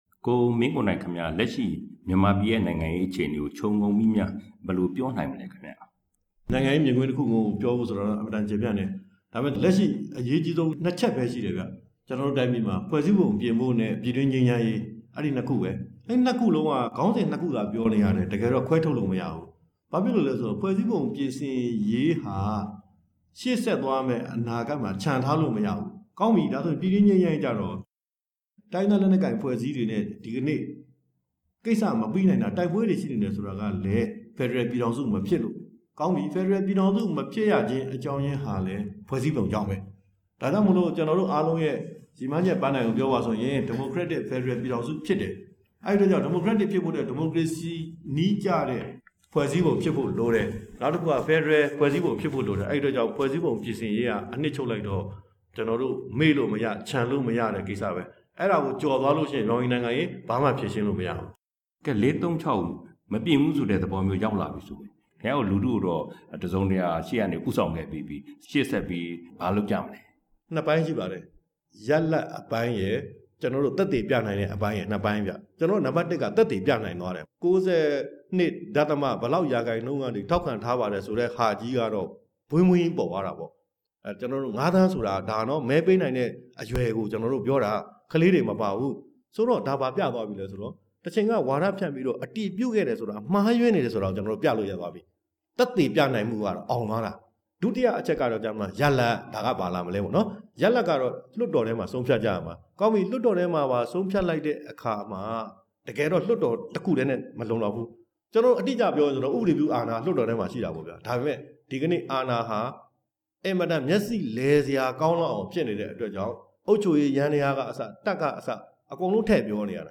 ကိုမင်းကိုနိုင်နဲ့ မေးမြန်းချက်